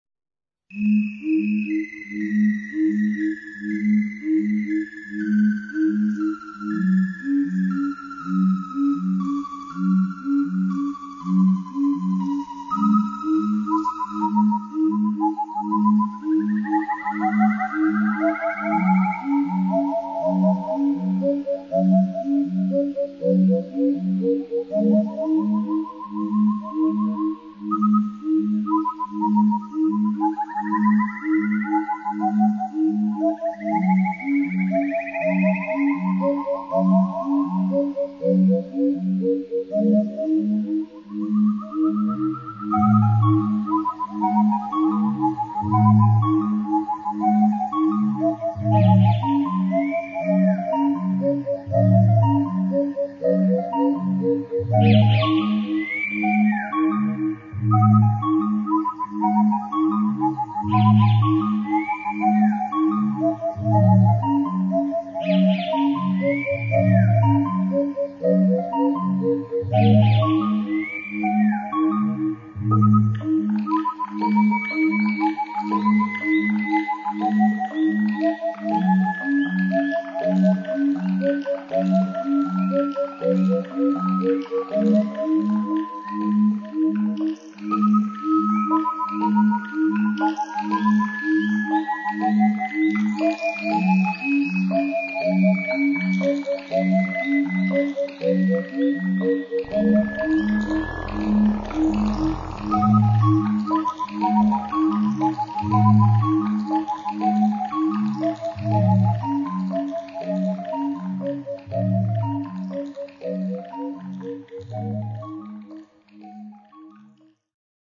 Insectes etc.